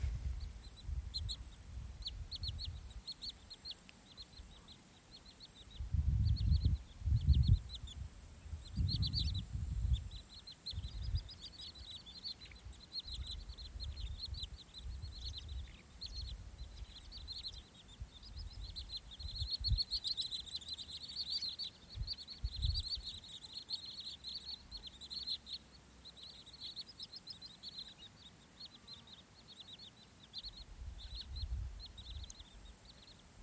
Purva tilbīte, Tringa glareola
Administratīvā teritorijaAlūksnes novads
Skaits30 - 35
StatussDzirdēta balss, saucieni